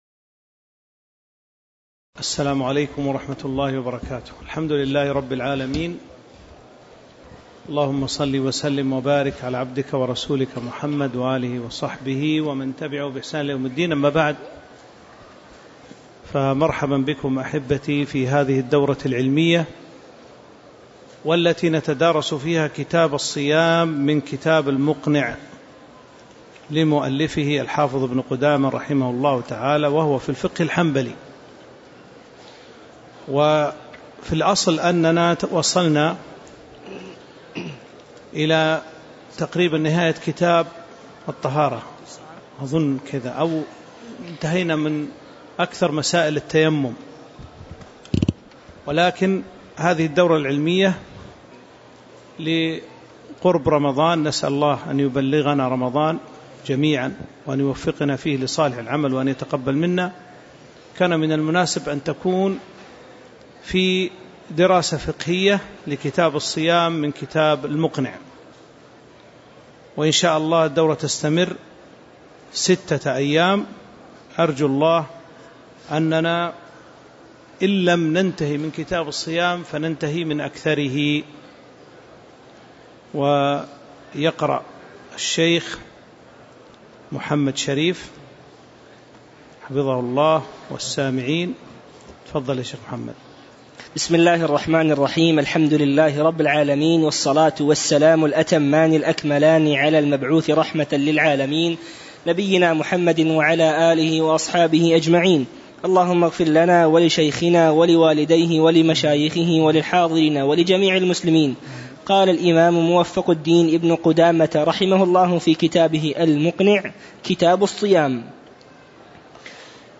تاريخ النشر ٢١ رمضان ١٤٤٥ هـ المكان: المسجد النبوي الشيخ